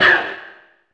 c_hunter_hit1.wav